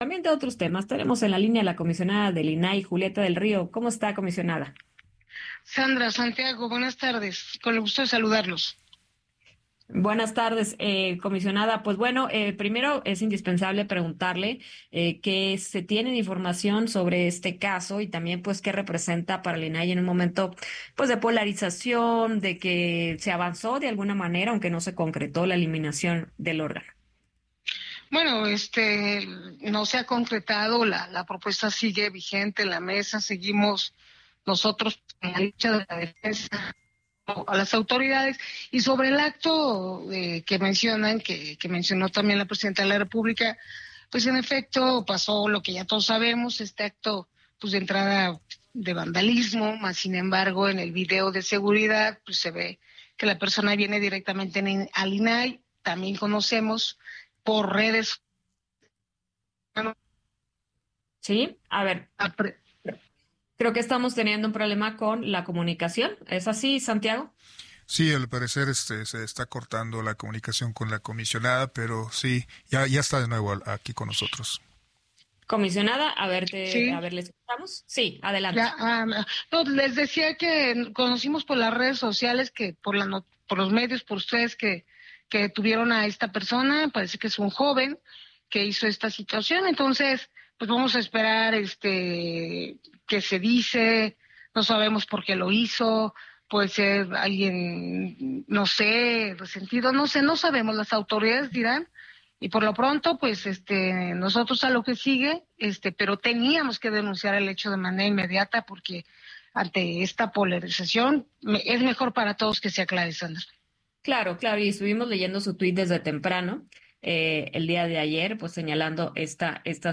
Entrevista
en Heraldo Radio sobre el estatus del INAI en la defensa de las libertades informativas de las y los mexicanos.